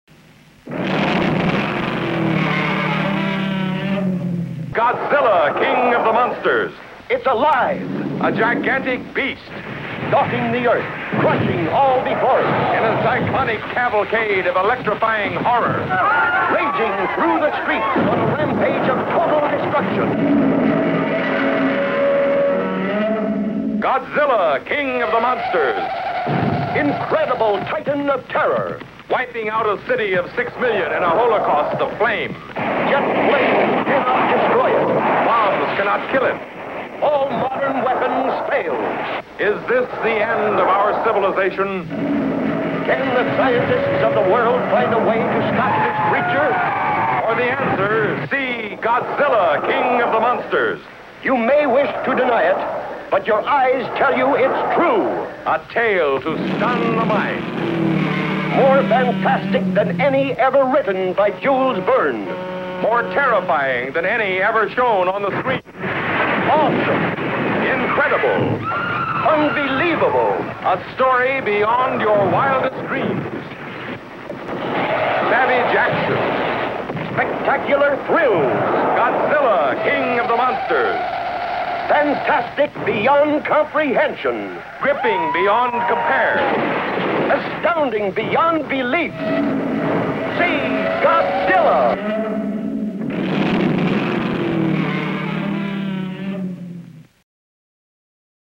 Oddly enough, Godzilla’s characteristic roar is not heard in these spots.
So, here they are…as listeners would have heard them back in 1956!
Godzilla King of the Monsters Radio Spots for 12, 50, and 100 seconds versions.